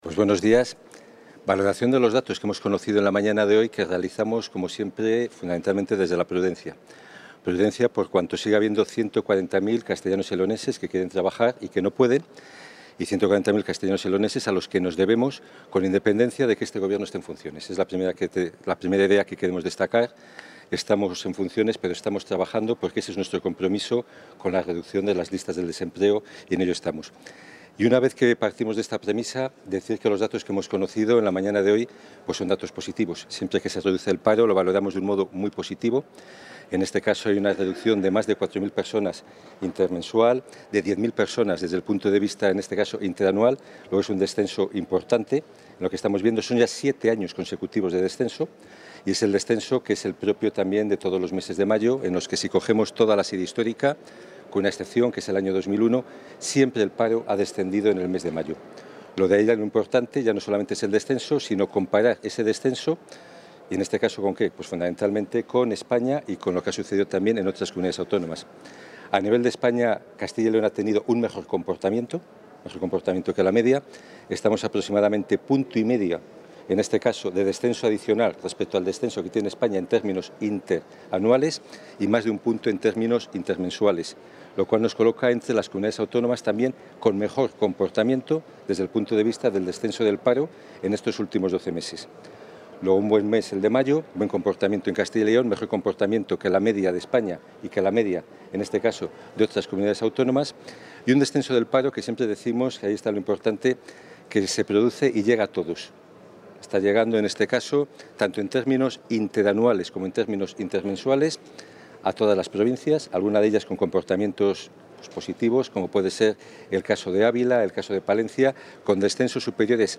Valoración de los datos del paro de mayo Contactar Escuchar 4 de junio de 2019 Castilla y León | El viceconsejero de Empleo y Diálogo Social, Mariano Gredilla, ha valorado hoy los datos del paro del mes de mayo.